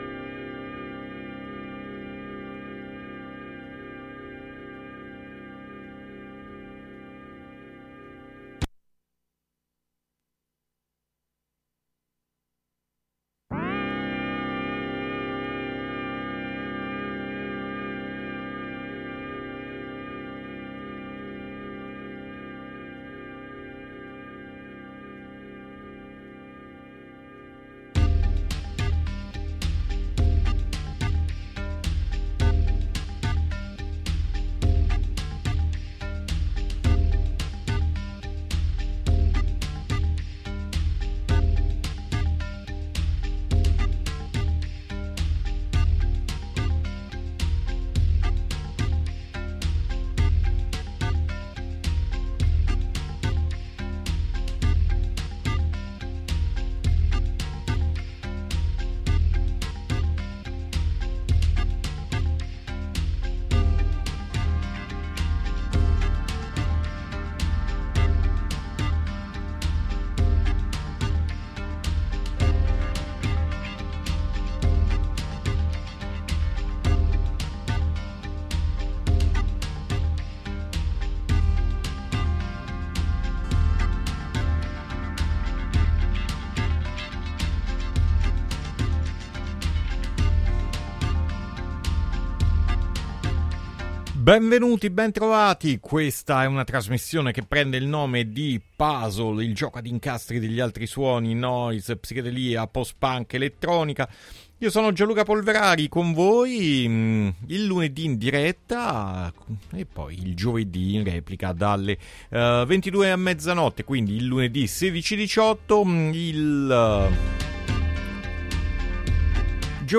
Le varie sfumature dell’elettronica, ma anche del rock, nelle loro forme più ricercate e meno scontate, hanno fatto parte della scaletta di Puzzle
Intervista